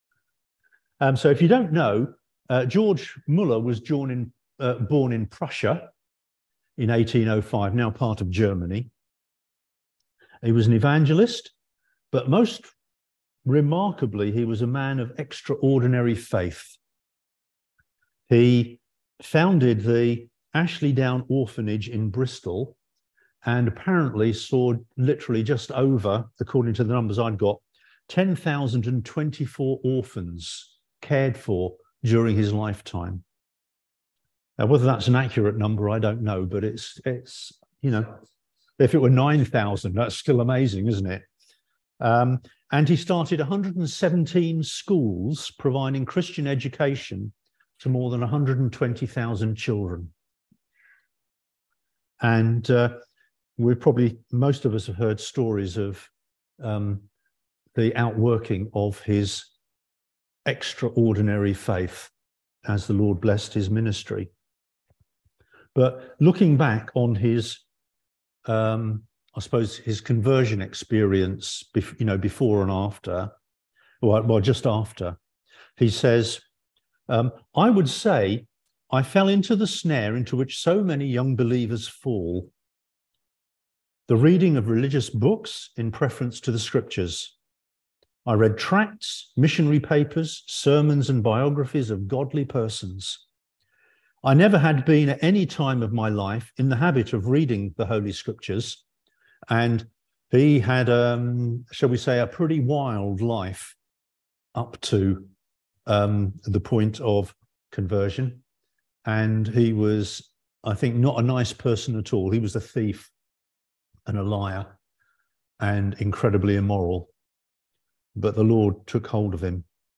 Passage: Psalm 119 Service Type: Sunday Service